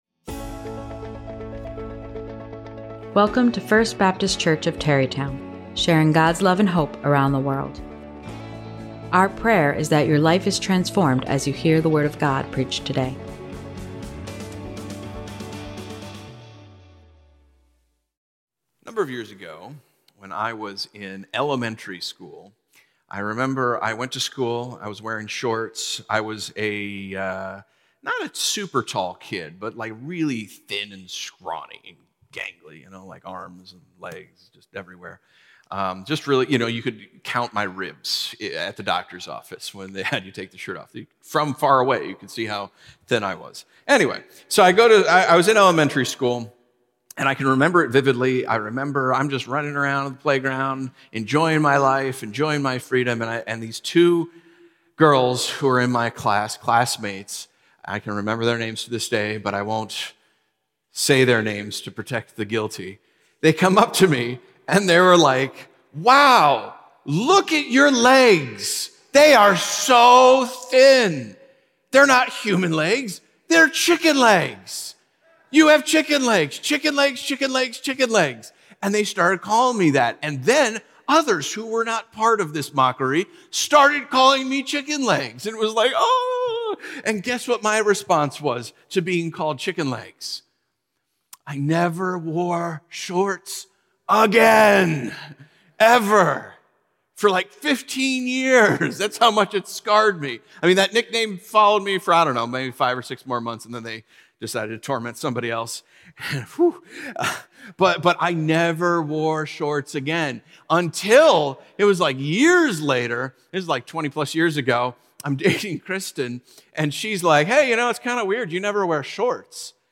Biblical Sermons